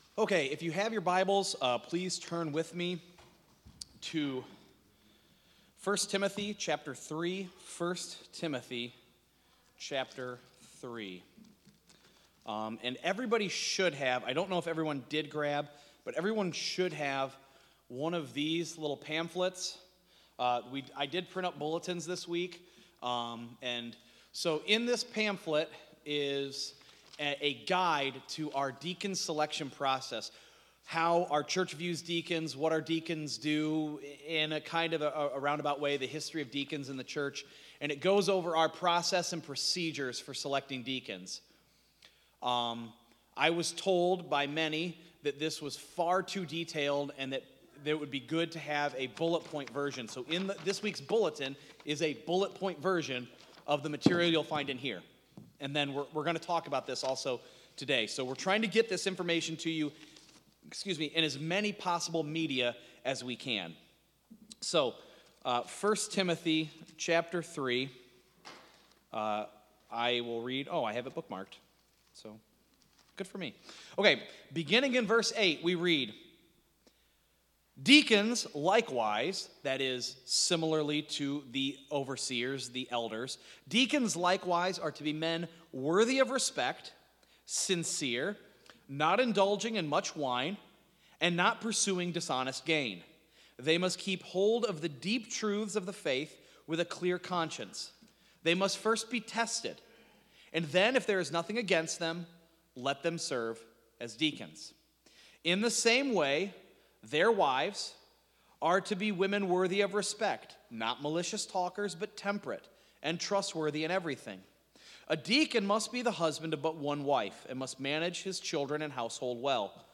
Sermons Archive